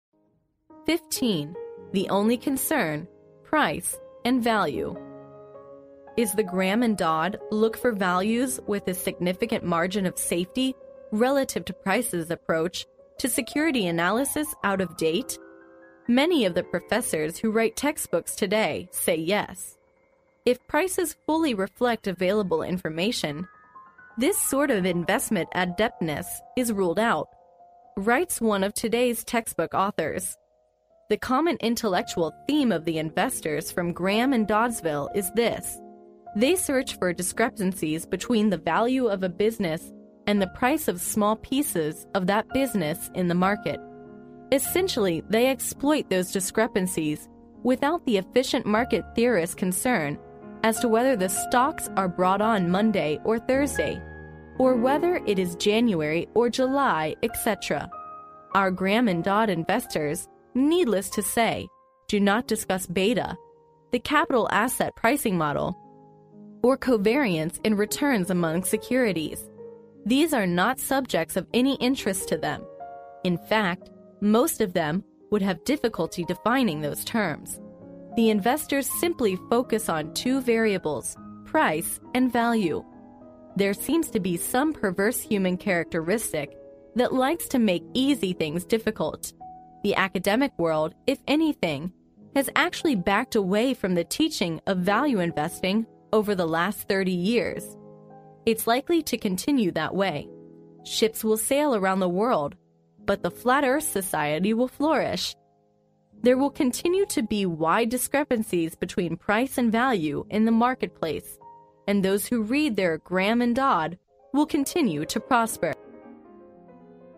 在线英语听力室历史英雄名人演讲 第76期:惟一的关注:价格与价值的听力文件下载, 《历史英雄名人演讲》栏目收录了国家领袖、政治人物、商界精英和作家记者艺人在重大场合的演讲，展现了伟人、精英的睿智。